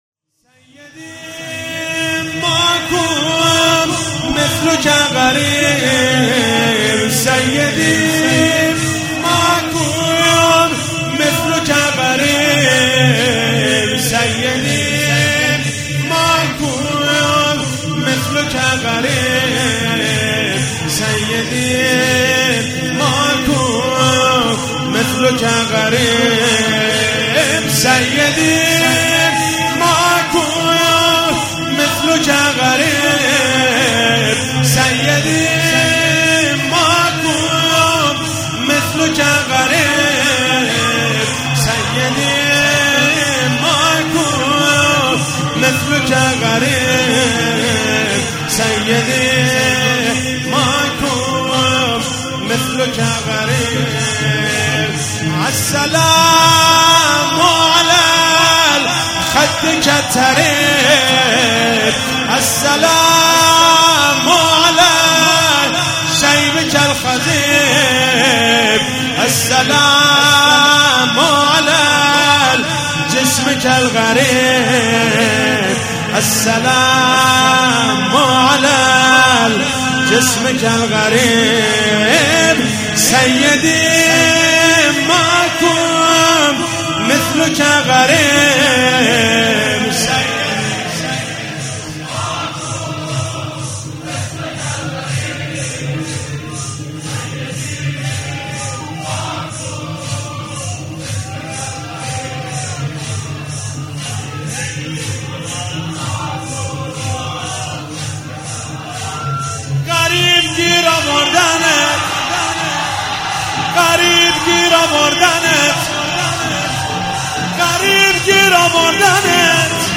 شب 3 فاطمیه 95 - شور - سیدی ماکو مثلک الغریب